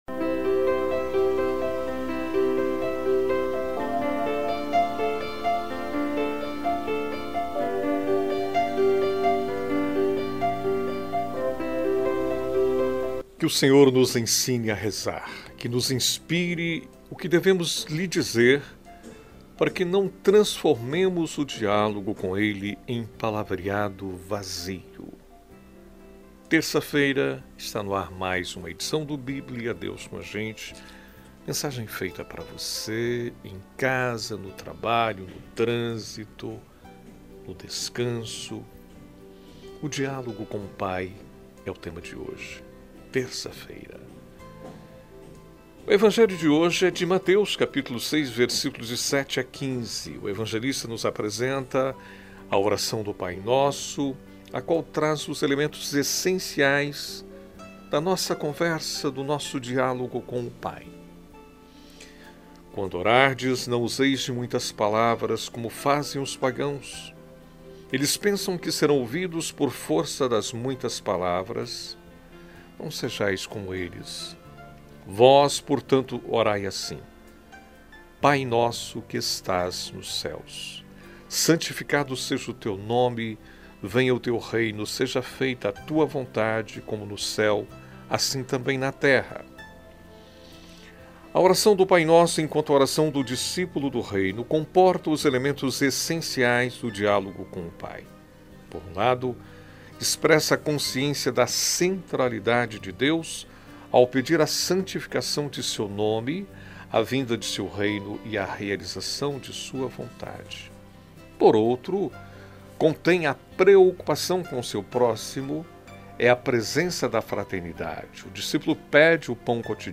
É um momento de reflexão diário com duração de aproximadamente 5 minutos, refletindo o evangelho do dia, indo ao ar de segunda a sexta